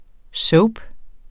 Udtale [ ˈsɔwb ]